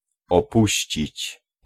Ääntäminen
US : IPA : [əˈbæn.dən]